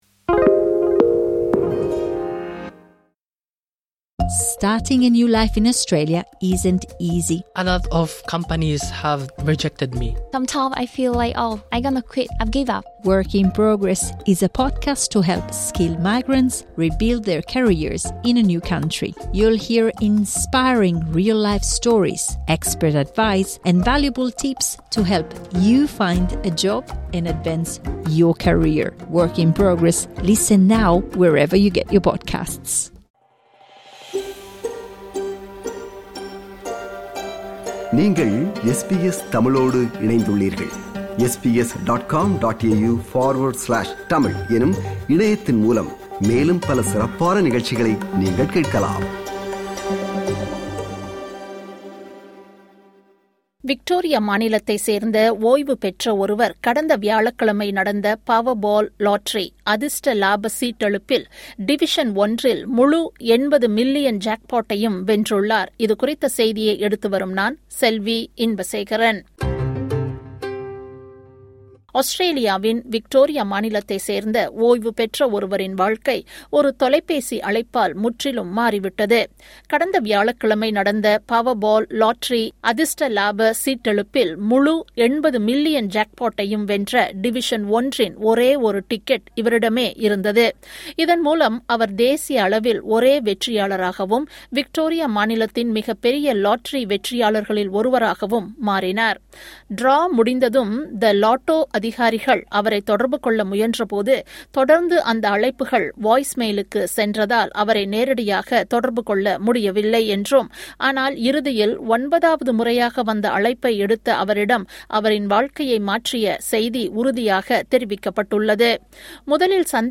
விக்டோரியா மாநிலத்தைச் சேர்ந்த ஓய்வுபெற்ற ஒருவர் கடந்த வியாழக்கிழமை நடந்த Powerball லாட்டரி divison ஒன்றில் முழு $80 மில்லியன் ஜாக்பாட்டையும் வென்றுள்ளார். இது குறித்த செய்தியை எடுத்து வருகிறார்